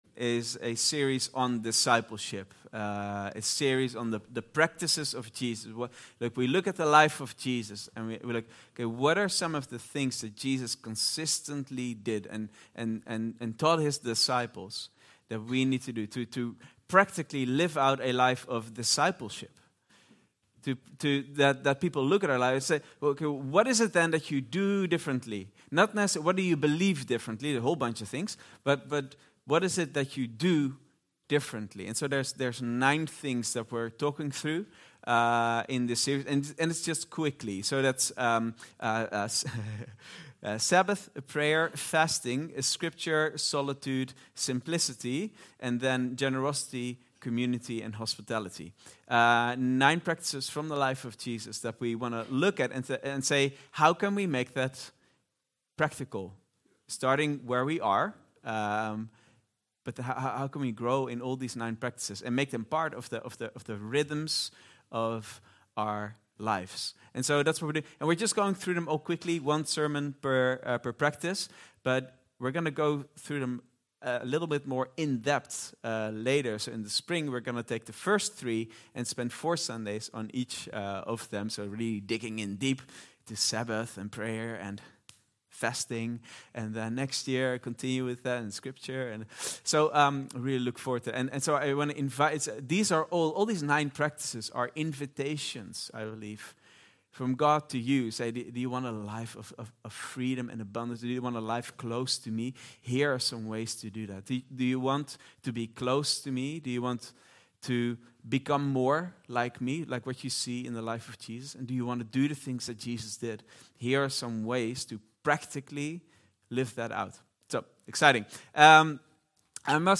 Vineyard Groningen Sermons